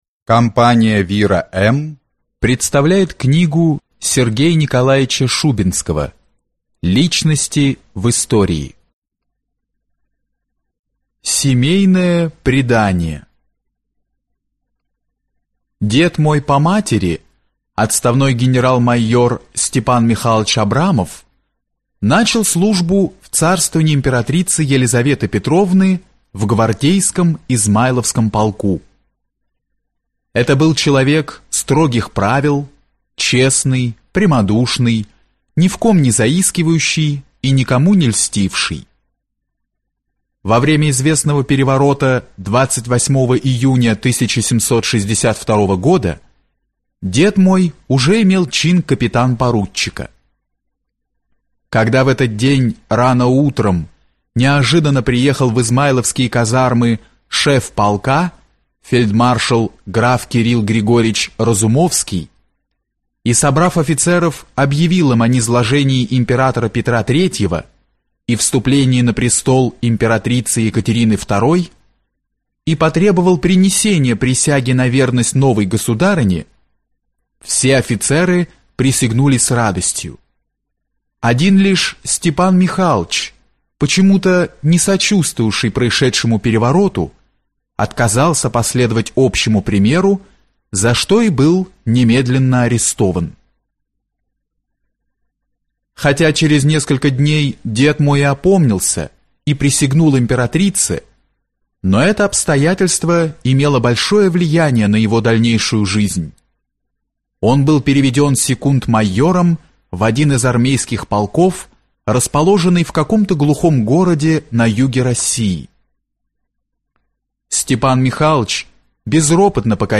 Аудиокнига Личности в истории | Библиотека аудиокниг